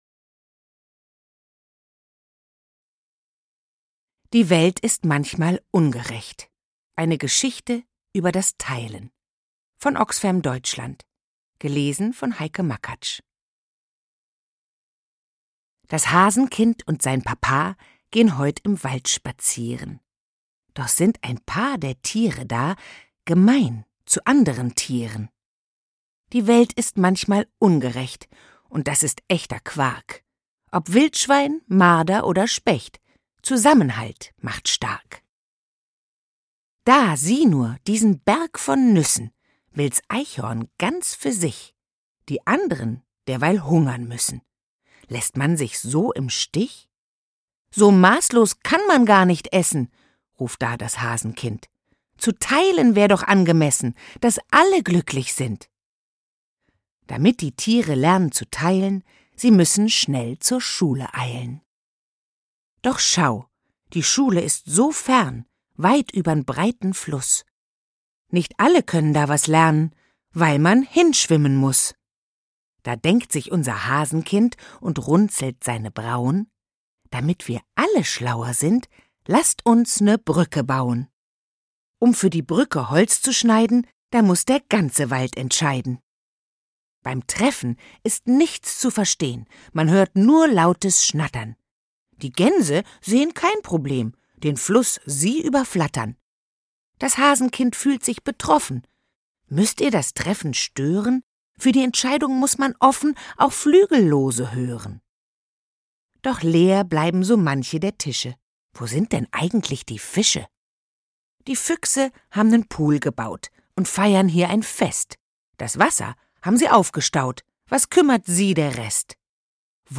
Unser Hörbuch für Sie